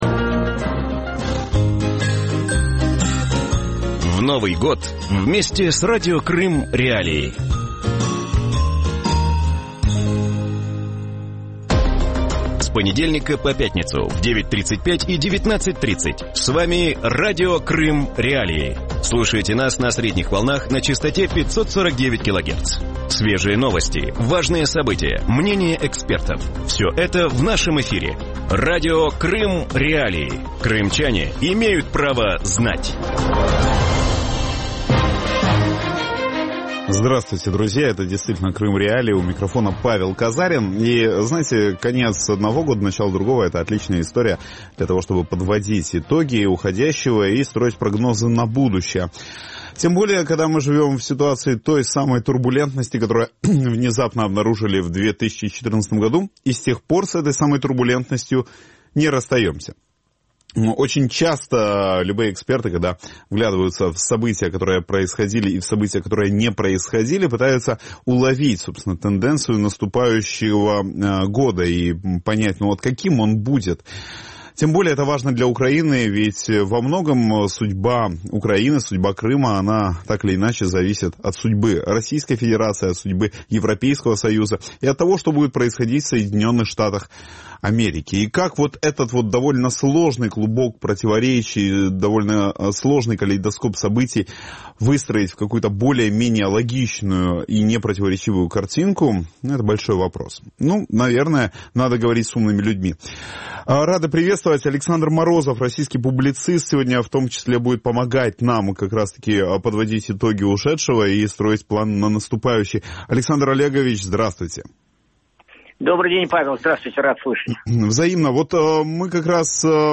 У вечірньому ефірі Радіо Крим.Реалії говорять про те, що очікує російську політичну систему в новому році. Чи варто сподіватися на політичні зміни, чи є ймовірність дострокових президентських виборів і чи будуть пам'ятати в наступному році про Крим?